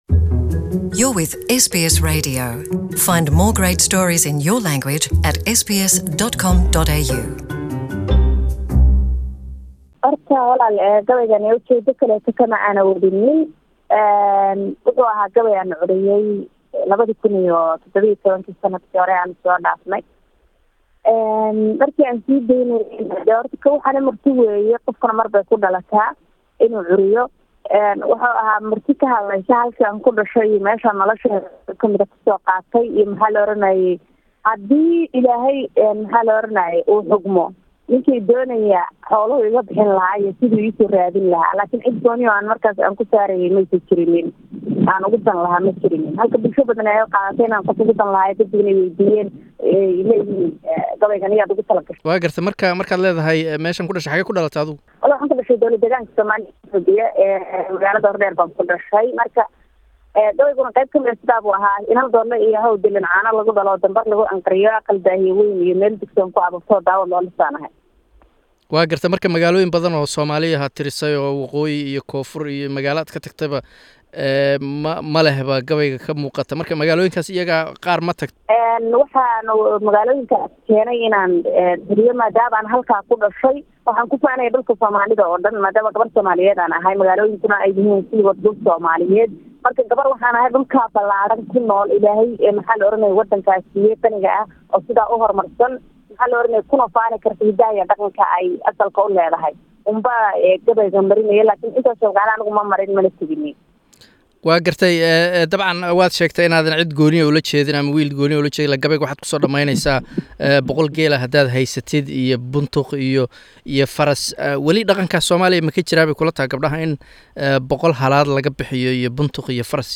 Interview:young poet